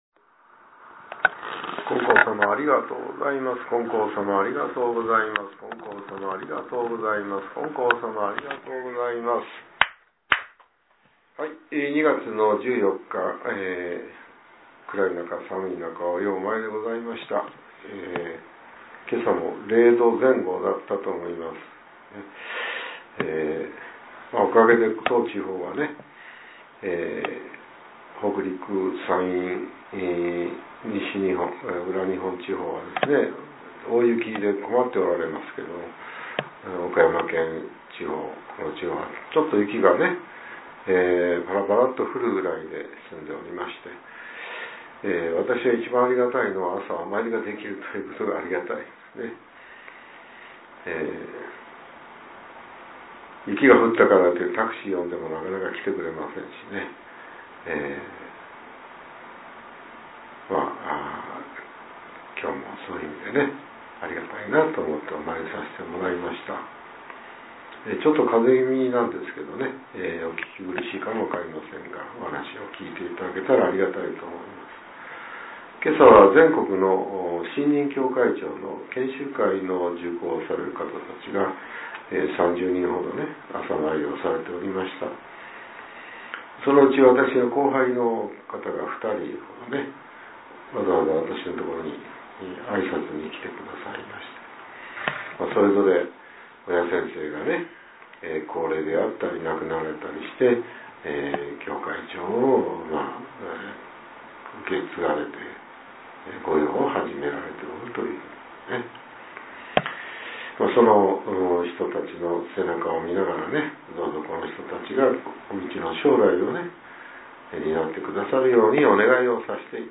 令和７年２月１４日（朝）のお話が、音声ブログとして更新されています。